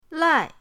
lai4.mp3